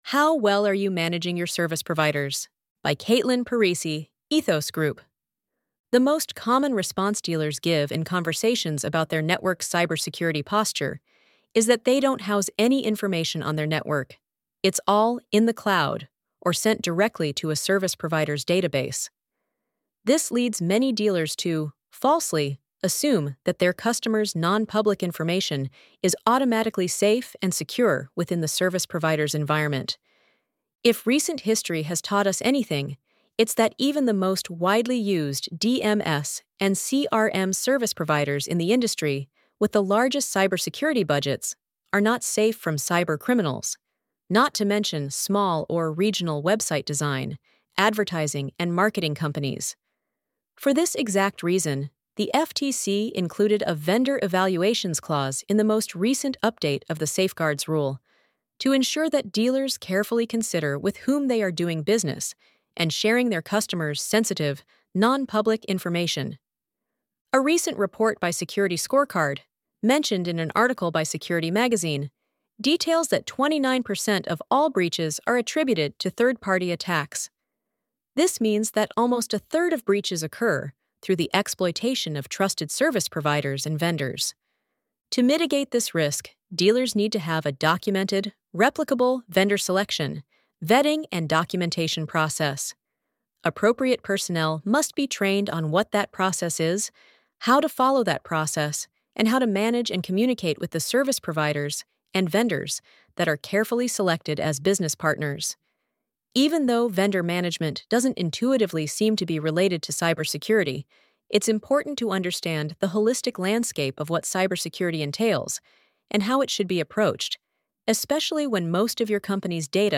ElevenLabs_How_Well_Are_You_Managing_Your_Service_Providers_.mp3